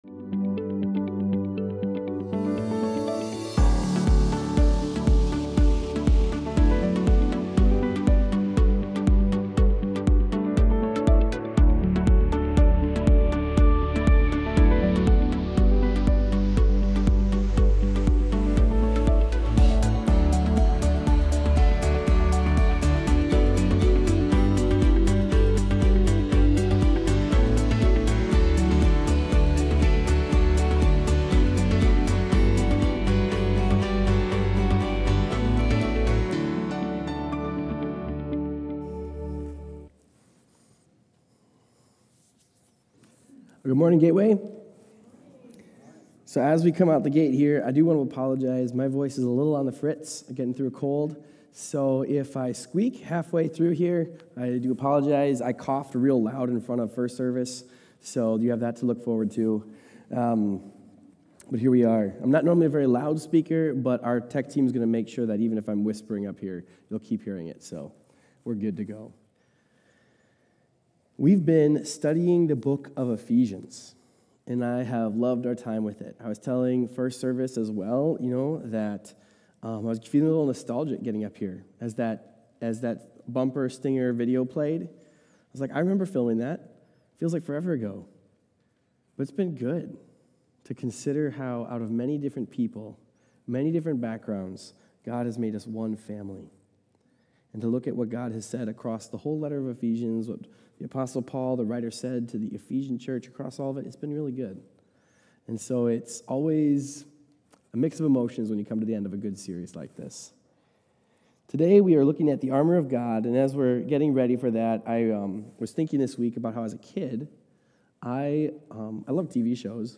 Armor-of-God-Sermon-12.1.24.m4a